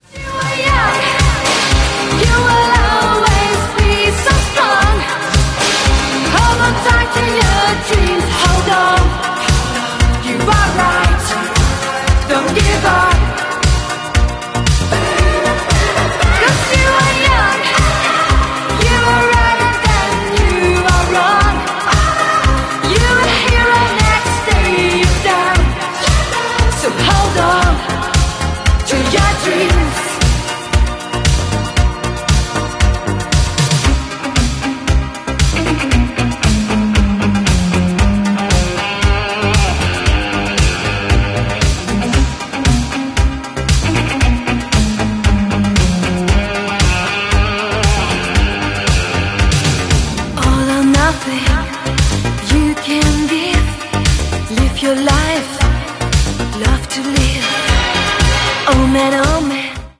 EU07-052 podczas II wystawy taboru